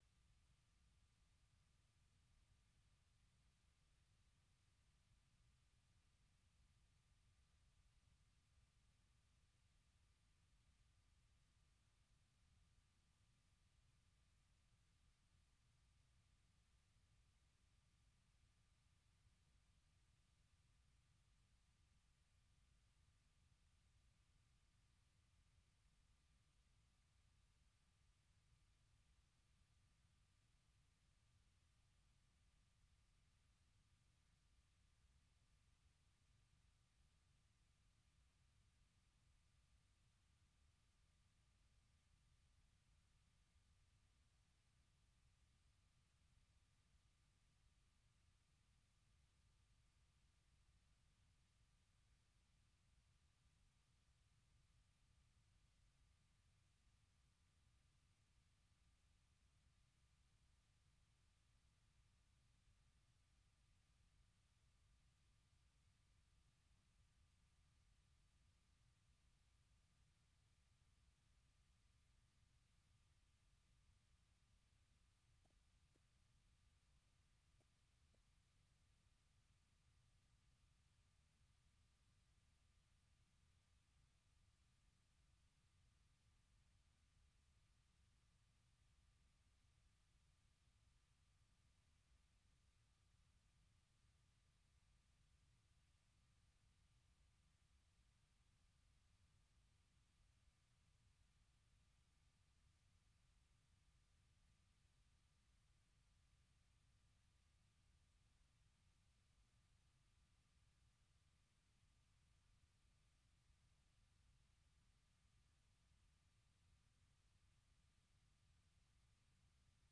A shirin namu na karfe 9 da rabi agogon Najeriya da Nijar, zaku ji labarai na yadda duniyar ta yini da rahotanni da dumi-duminsu, sannan mu kan bude muku layuka domin ku bugo ku bayyana mana ra’ayoyinku kan batutuwan da suka fi muhimmanci a wannan rana, ko kuma wadanda ke ci muku tuwo a kwarya.